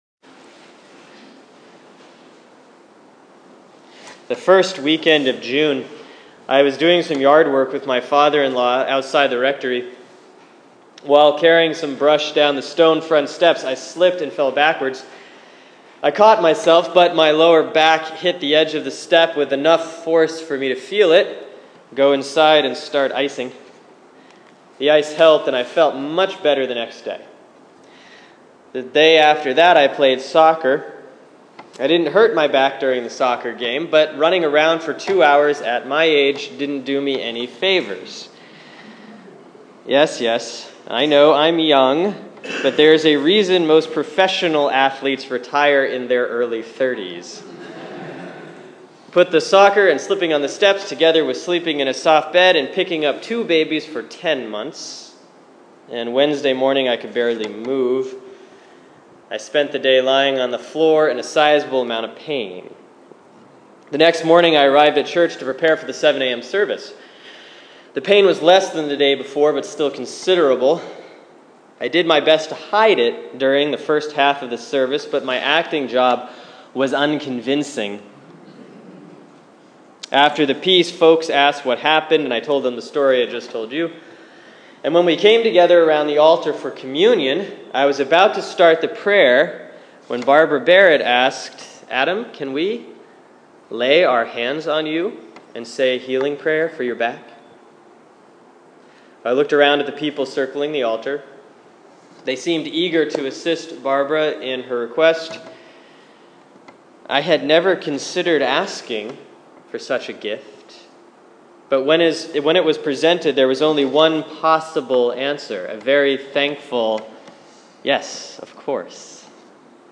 Sermon for Sunday, July 5, 2015 || Proper 9B || 2 Corinthians 12:2-10